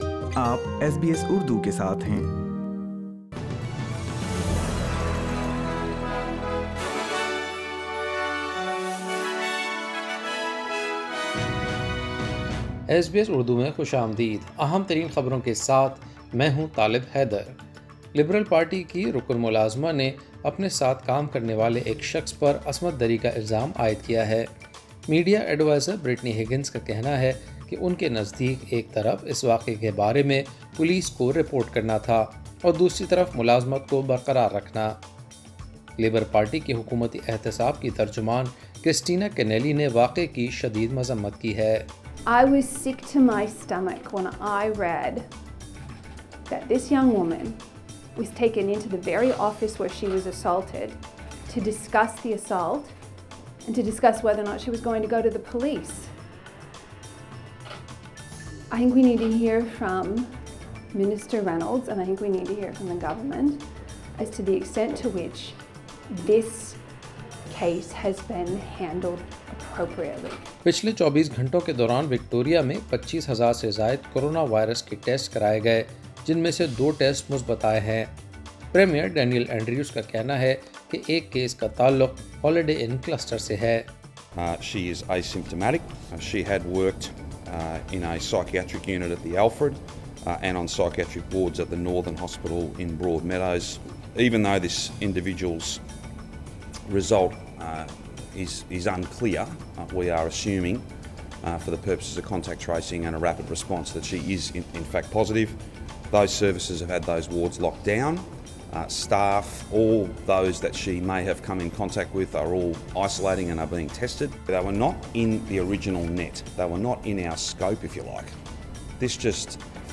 ایس بی ایس اردو خبریں 15 فروری 2021